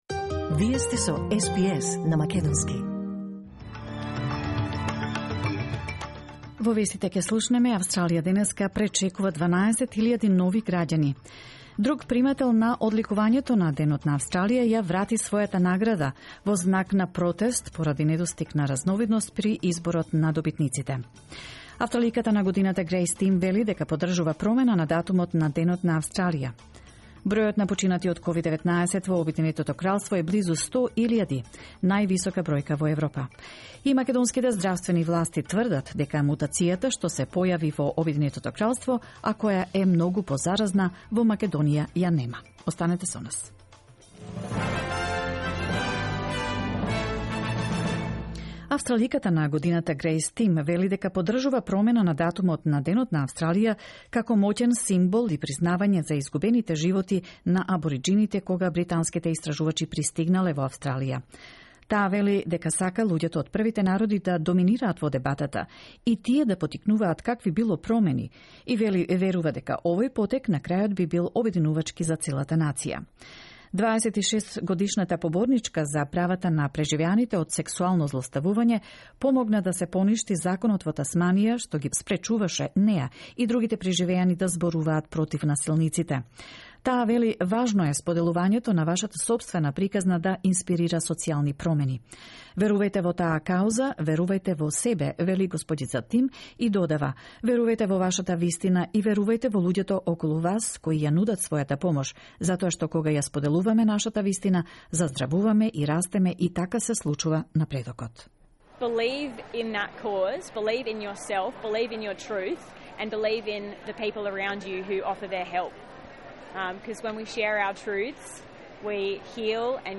SBS News in Macedonian 26 January 2021